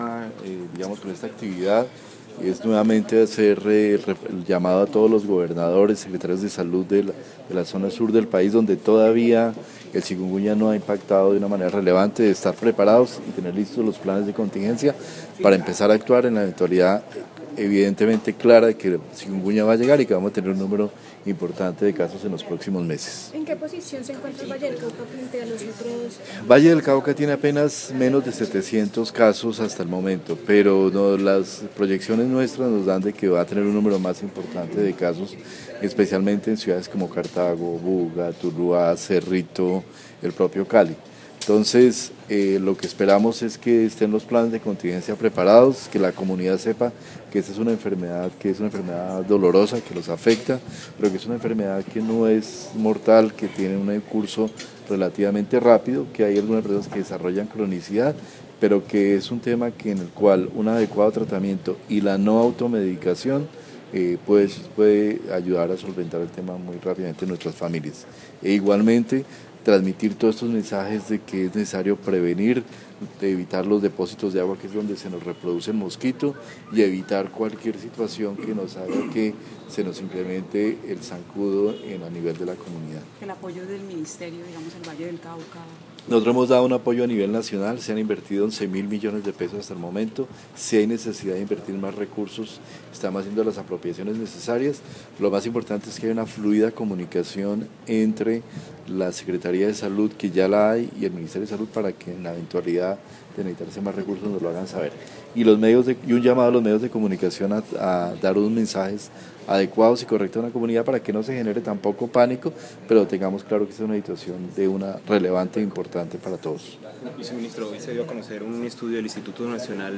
Audio, Viceministro Fernando Ruiz da recomendación a la comunidad sobre chikunguña en Cali